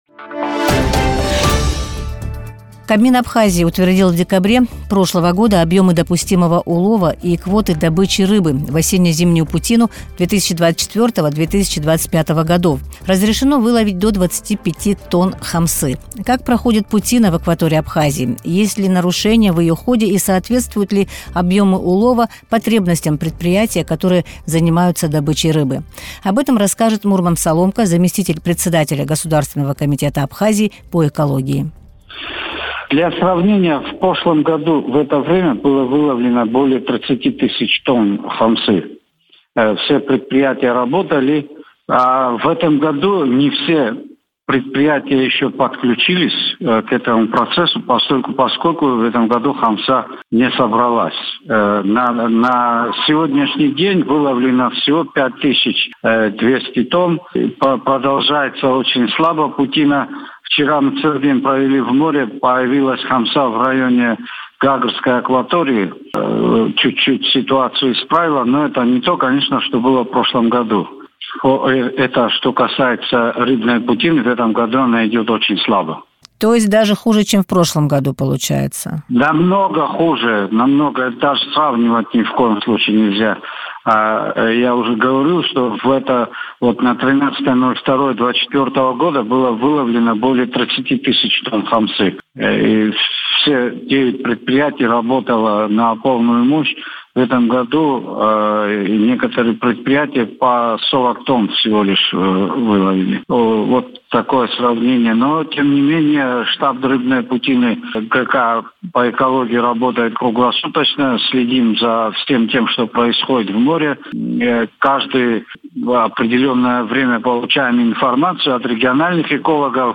Зампредседателя Госкомитета Абхазии по экологии Мурман Соломко в интервью радио Sputnik рассказал, как проходит хамсовая путина.